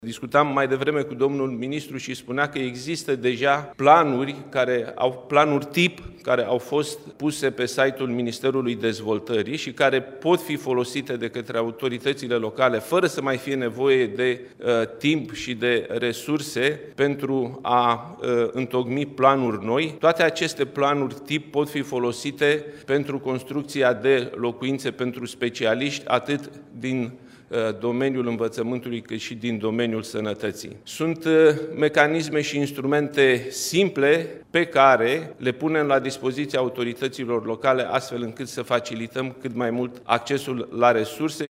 Premierul Nicolae Ciucă: